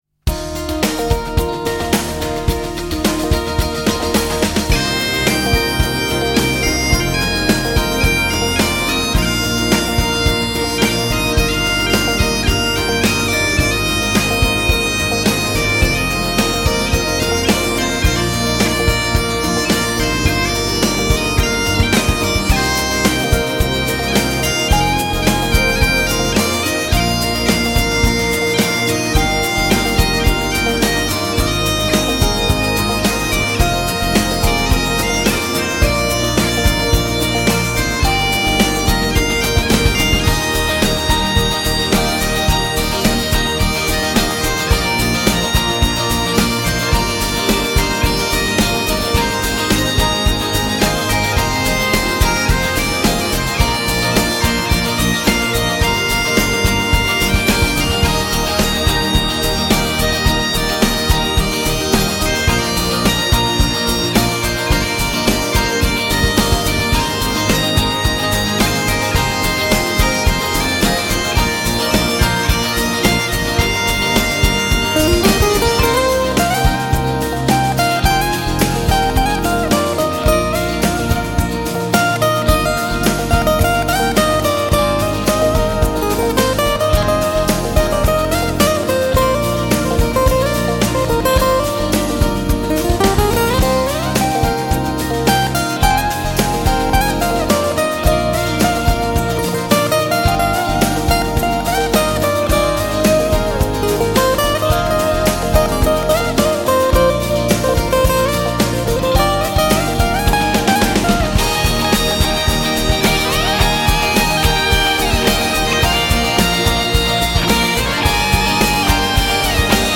Keyboards, Guitar, Backing Vocals
Bagpipes, Guitar, Whistles, Backing Vocals